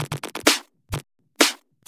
Index of /VEE/VEE Electro Loops 128 BPM
VEE Electro Loop 465.wav